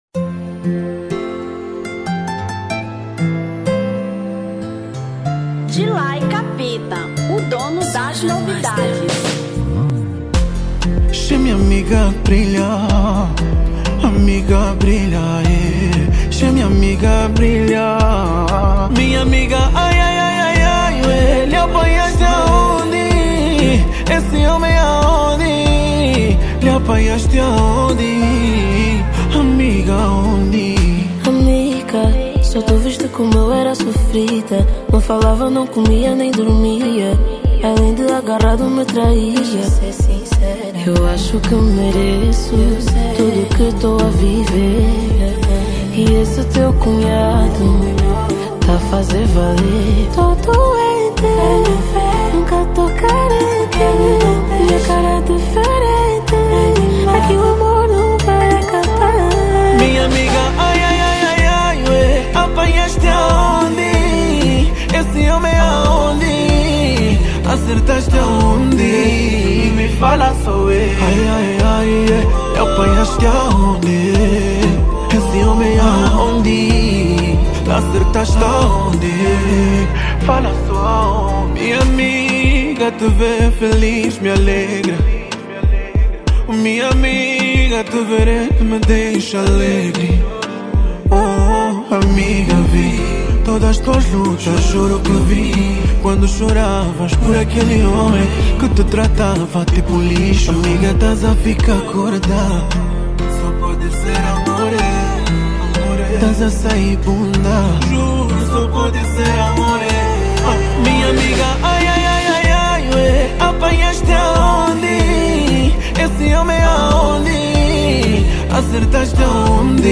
Kizomba 2025